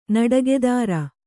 ♪ naḍagedāra